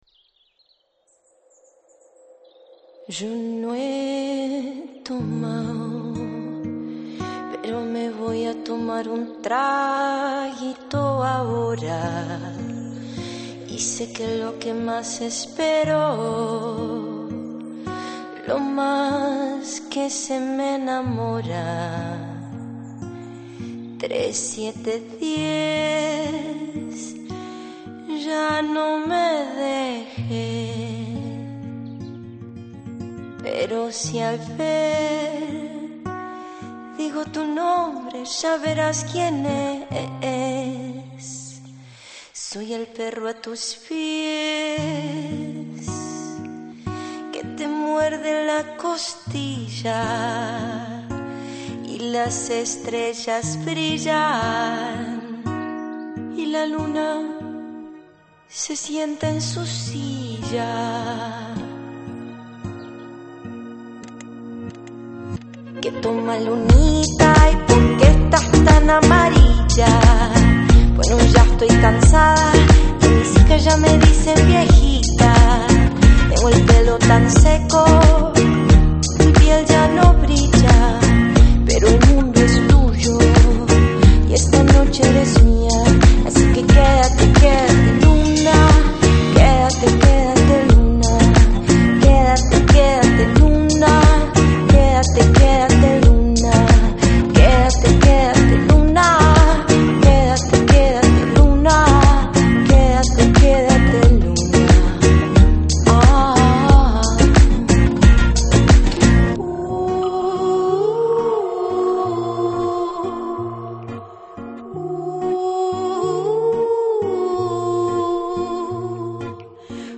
Жанр: Organic House, Progressive House, World